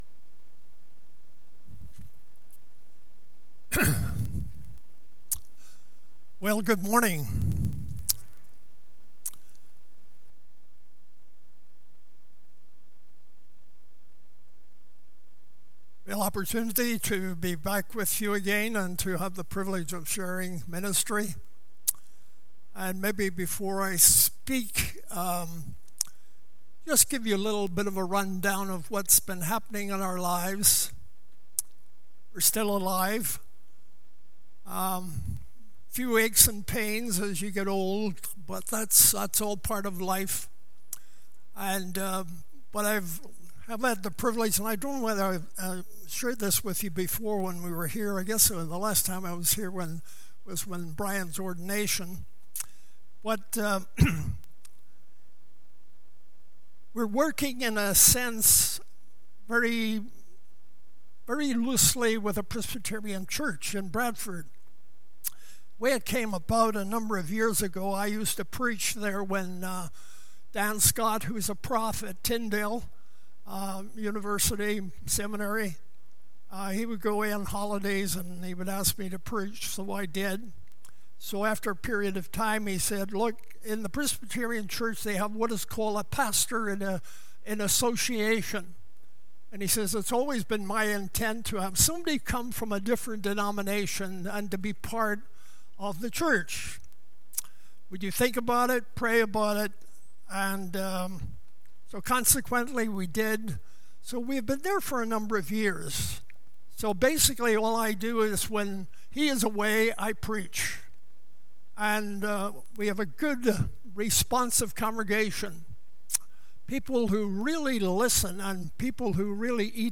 2020 Jesus Our Great High Priest BACK TO SERMON LIST Preacher